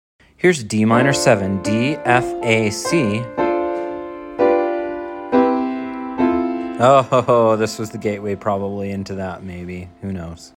Respect it, but not Mp3 Sound Effect Dm7. Respect it, but not from a distance. D minor seventh.